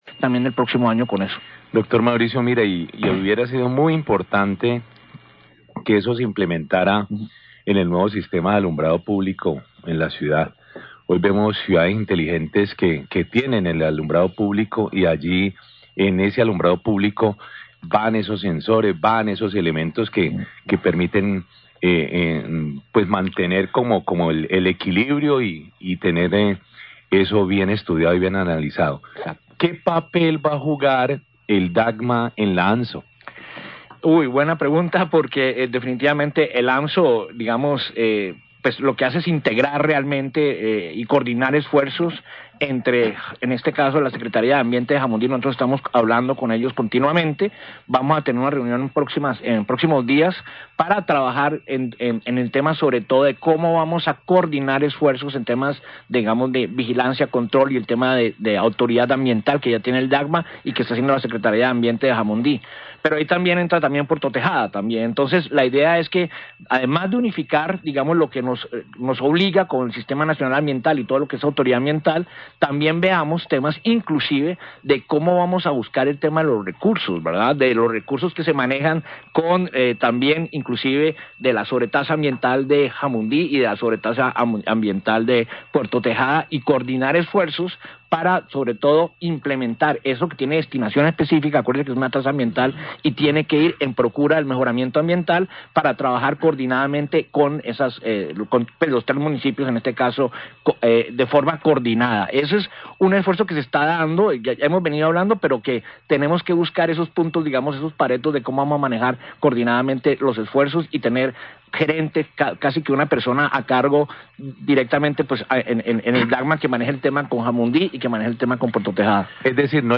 entrevista
En entrevista, el director del DAGMA, Mauricio Mira habló acerca del papel de la autoridad ambiental en la AMSO.